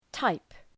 Προφορά
{taıp}